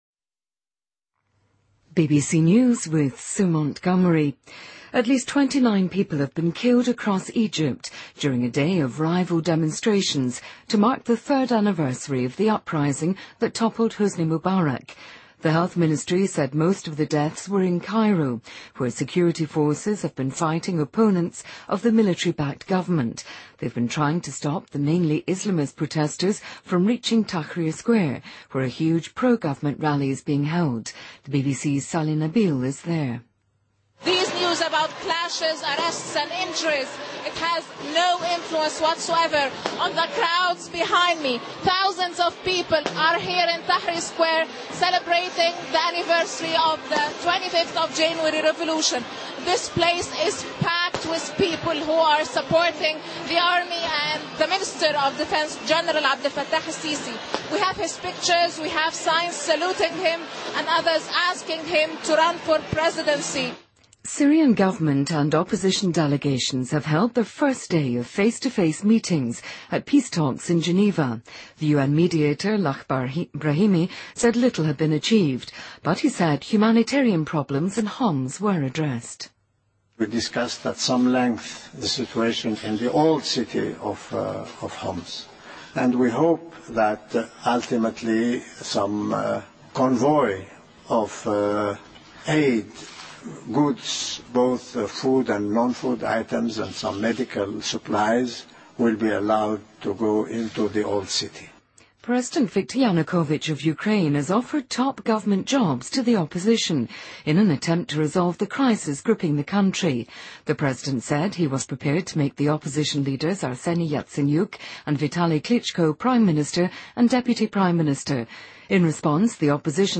BBC news,2014-01-26